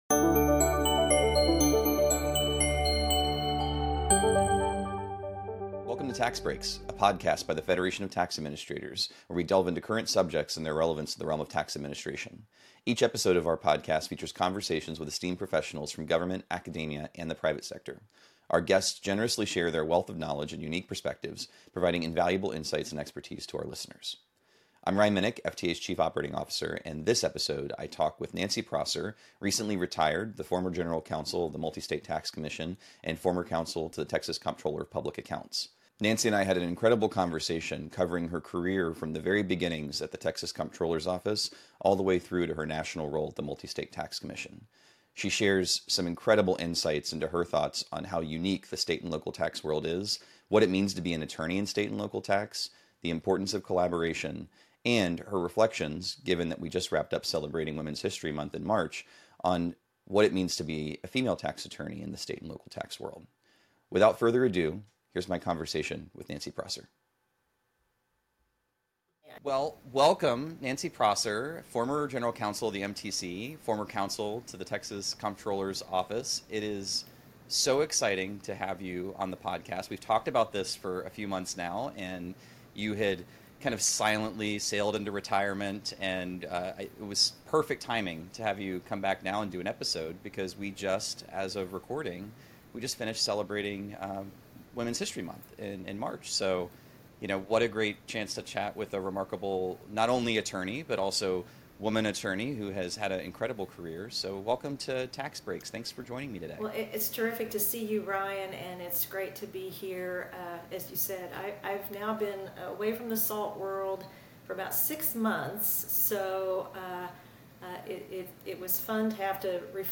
Each episode of our podcast features conversations with esteemed professionals from government, academia, and the private sector.